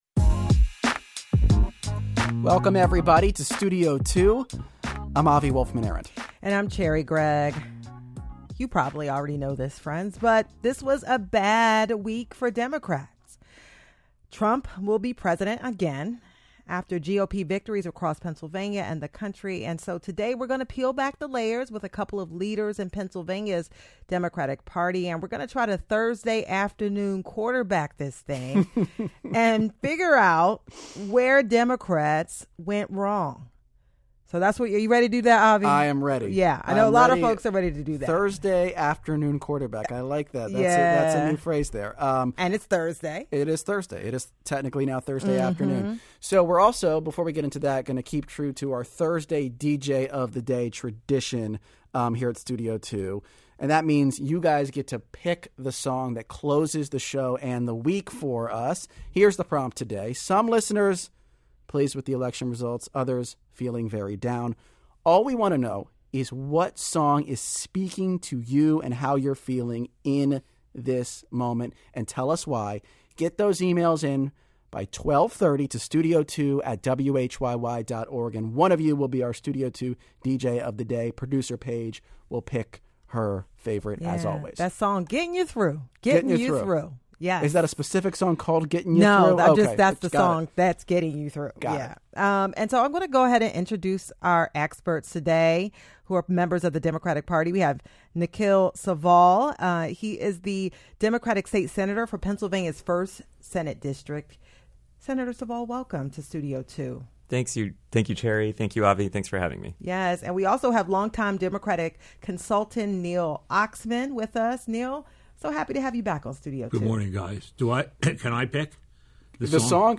Q&A with former EPA administrator Christie Todd Whitman on Trump's budget cuts - WHYY